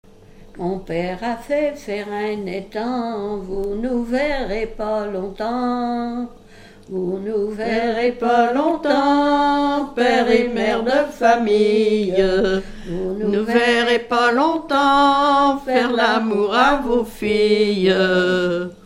Fonction d'après l'analyste gestuel : à marcher ;
Genre laisse
Pièce musicale inédite